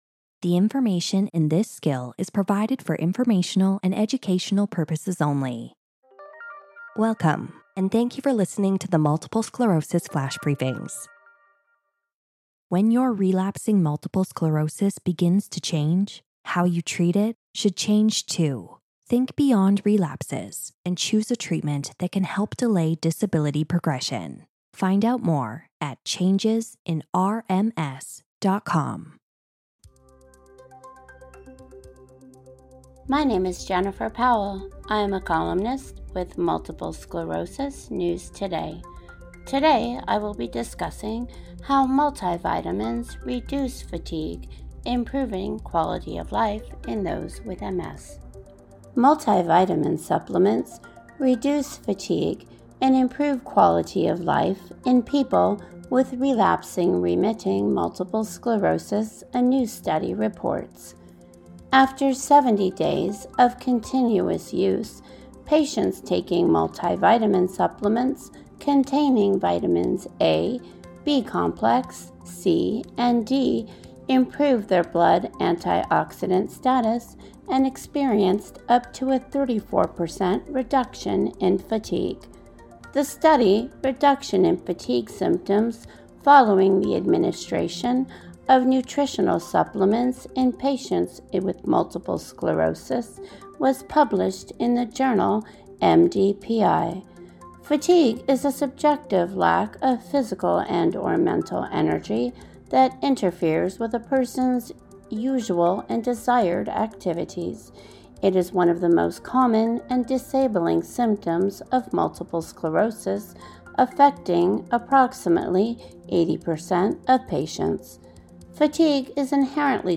reads the column